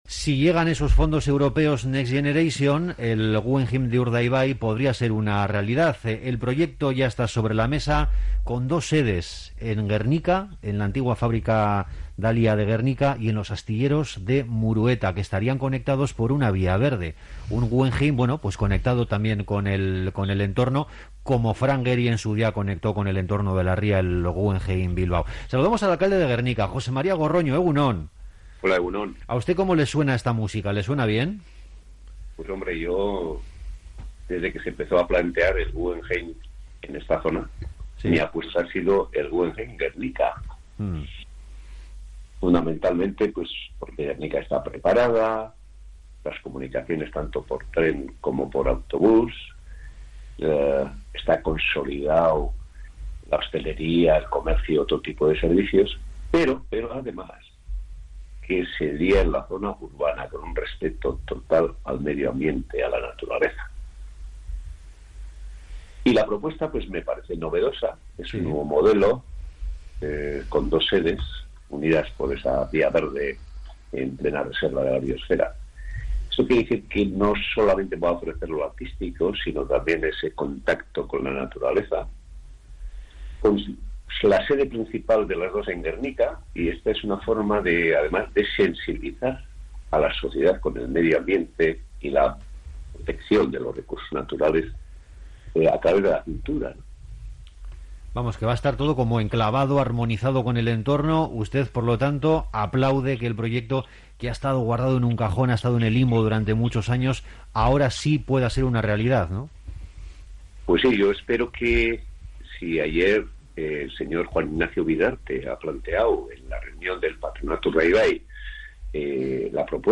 Entrevista en Onda Vasca-Grupo Noticias al alcalde de Gernika